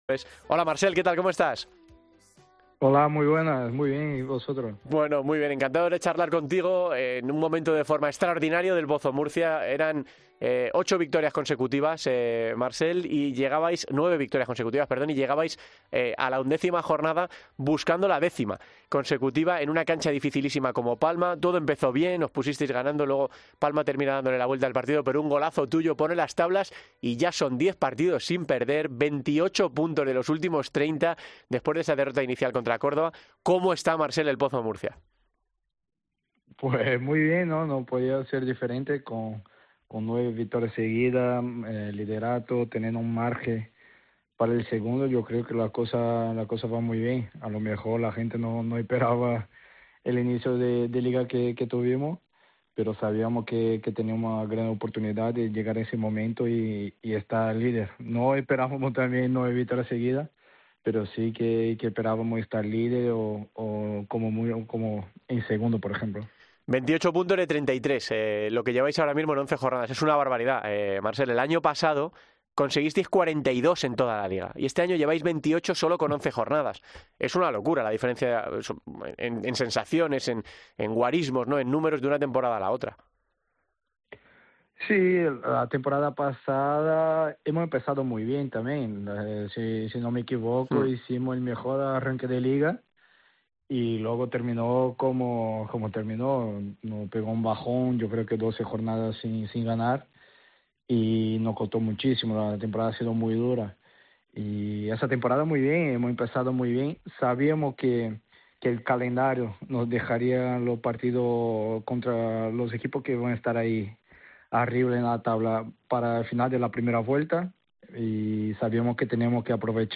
En una entrevista en el Podcast Futsal COPE